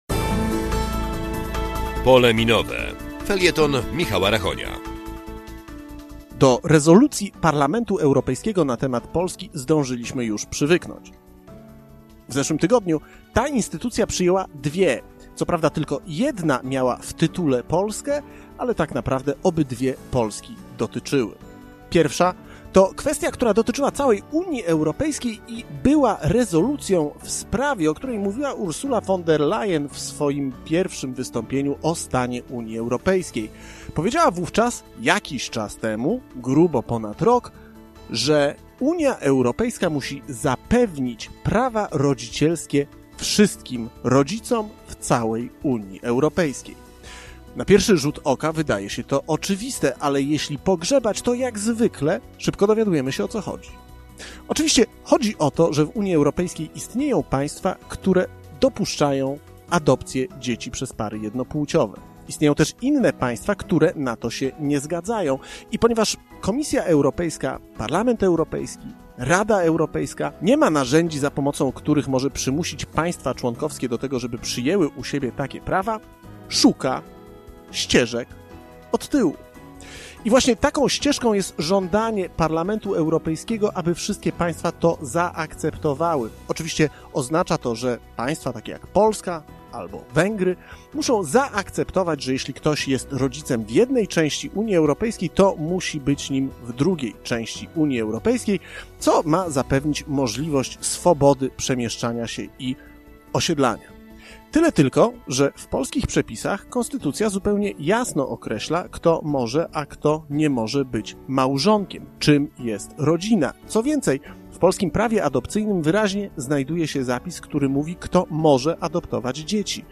W Radiu Zachód w każdą sobotę po godz. 12:15.
"Pole Minowe" - felieton polityczny